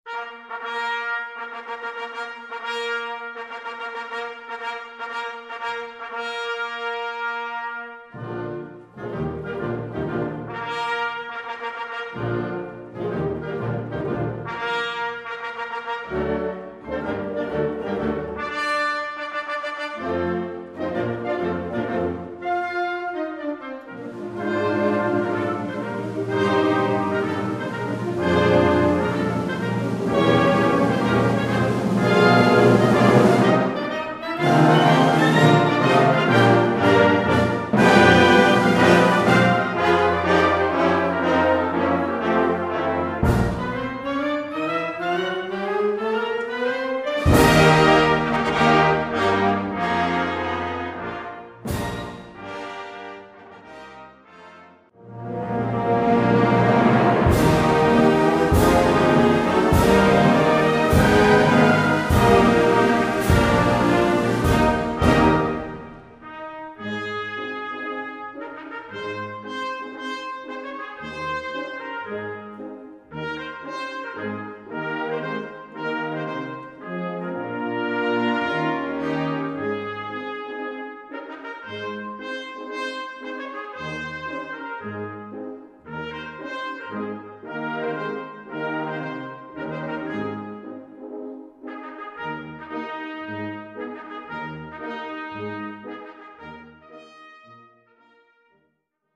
Violoncelle et Piano